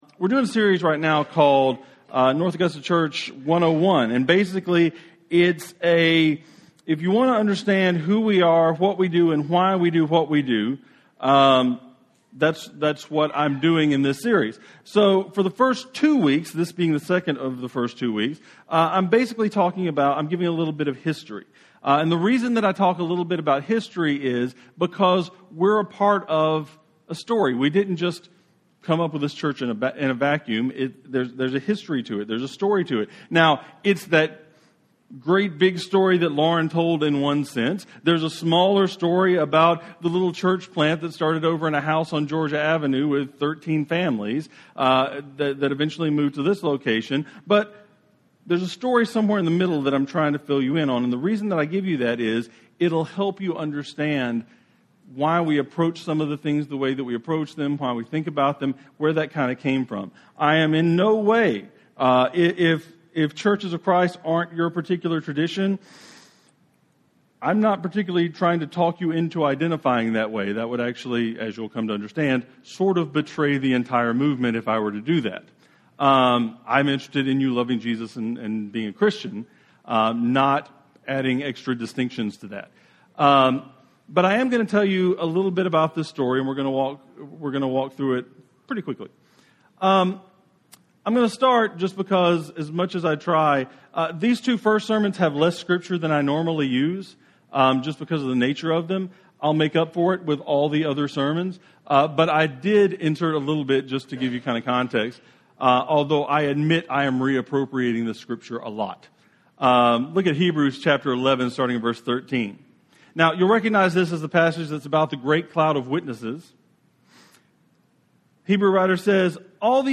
Sermons - North Augusta Church